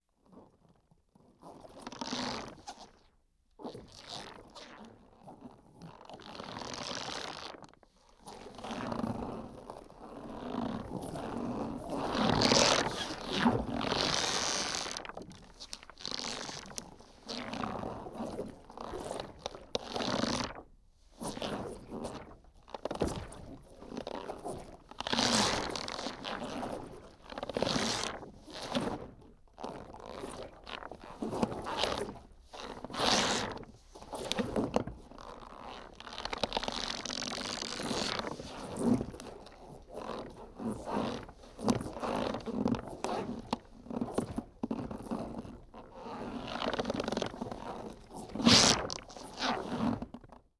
Rubber Stretches; Rubber Processed Stretching And Bending. - Cartoon, Stretchy Rubber